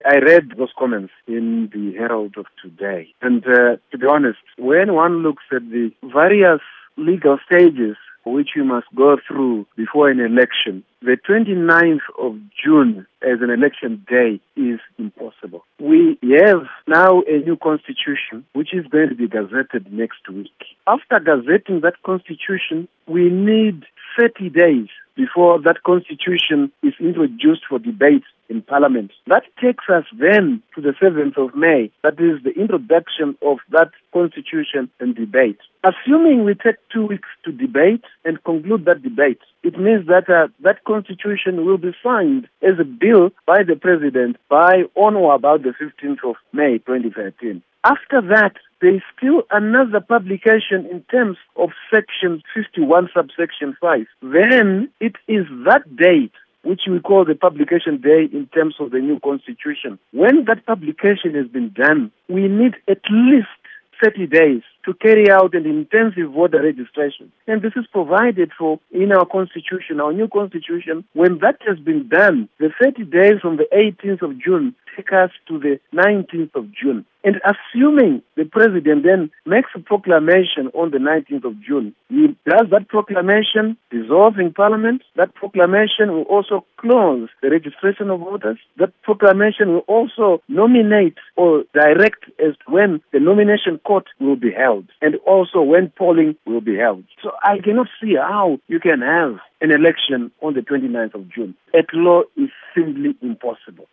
Interview With Eric Matinenga